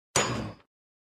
Звуки ифрита
На этой странице собраны звуки Ифрита — одного из самых опасных существ Нижнего мира в Minecraft. Здесь вы можете скачать или слушать онлайн его угрожающее рычание, звуки атак, возгорания и другие эффекты.